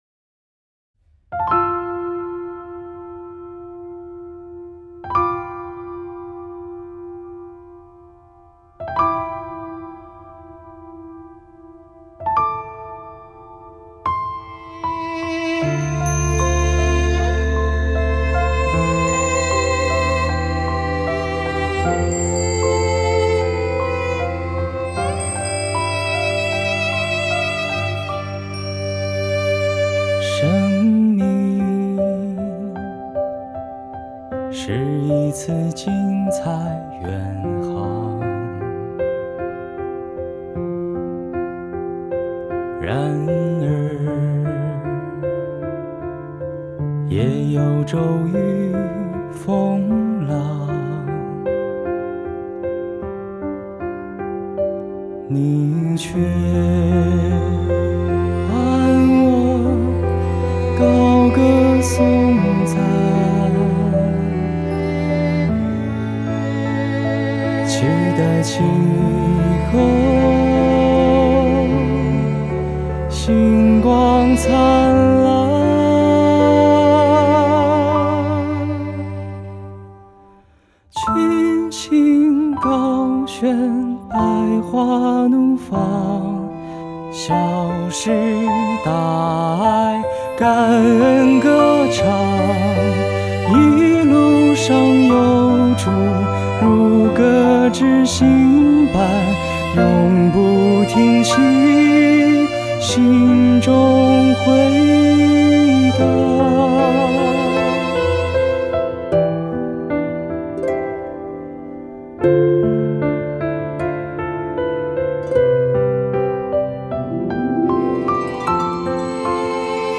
【原创圣歌】